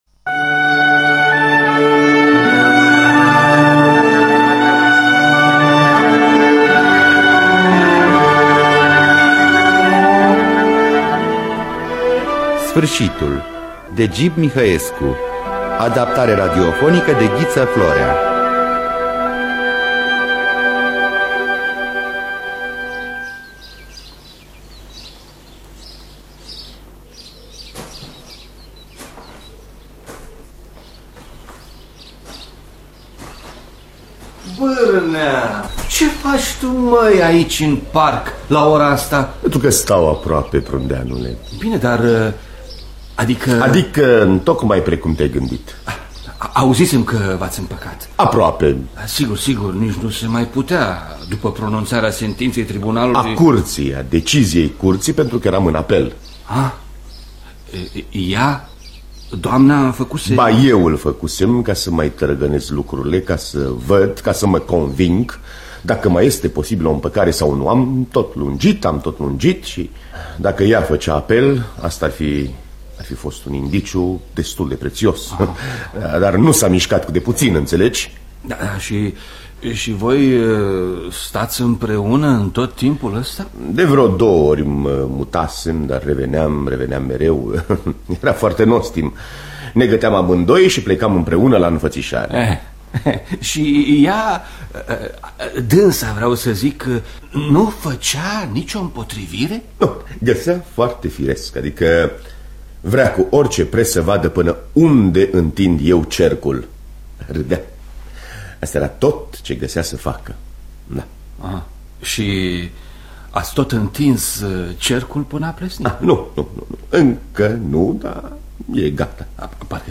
Adaptarea radiofonică de Ghiță Florea.